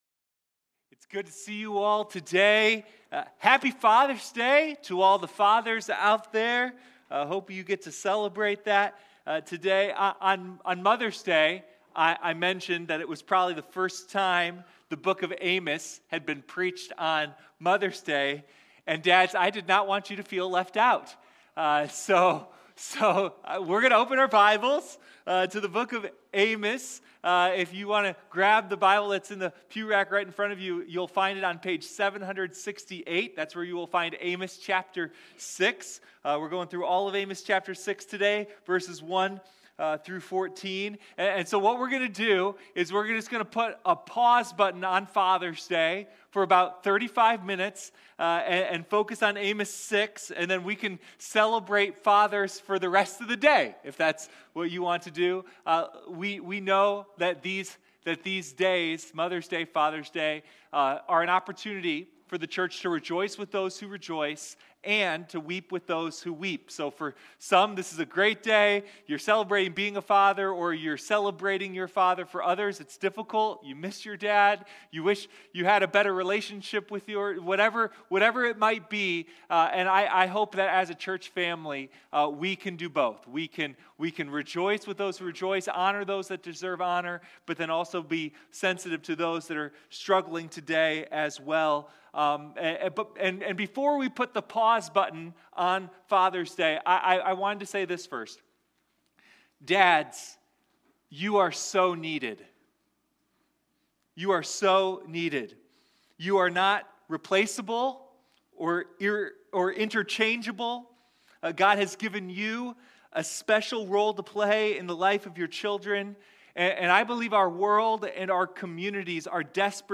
Sunday Morning Amos: The Roar of Justice